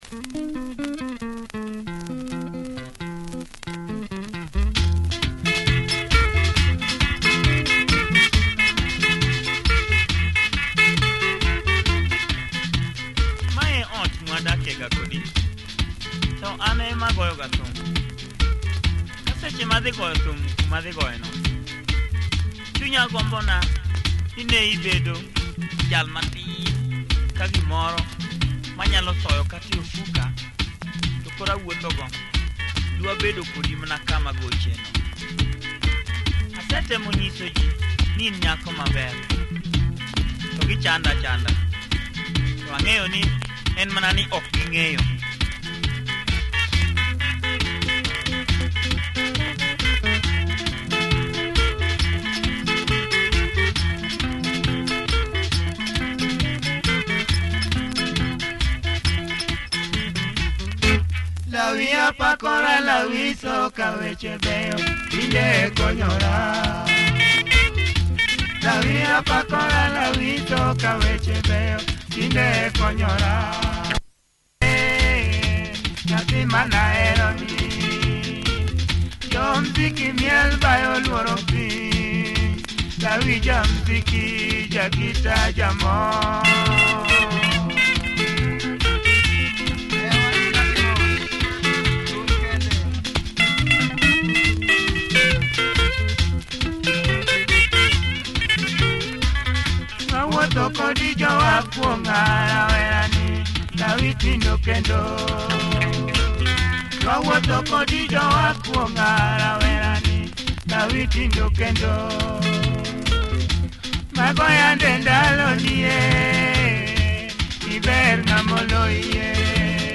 Killer luo stepper, for the clubs!